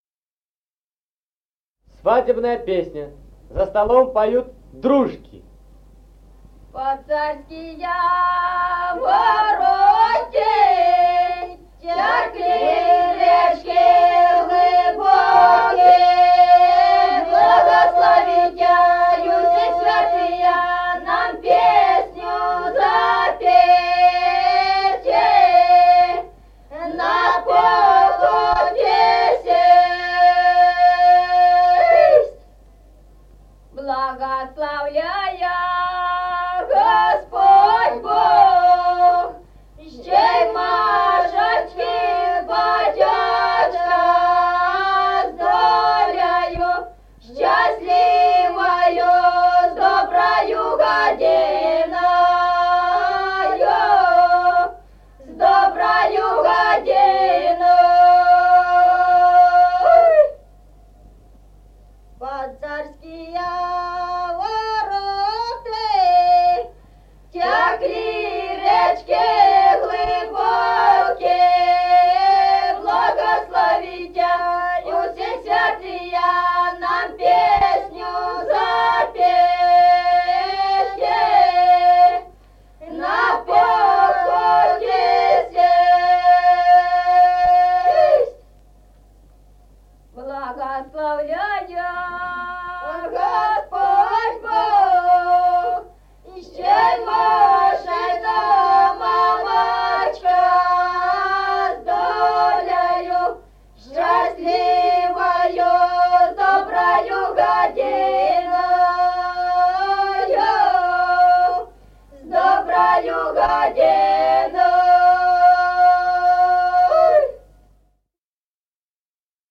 Музыкальный фольклор села Мишковка «Под царские вороты», свадебная.